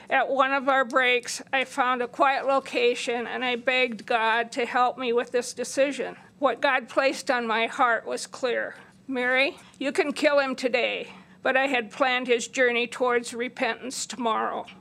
A Sioux Falls woman told the committee what it felt like to serve as a juror on a capital punishment case roughly 40 years ago.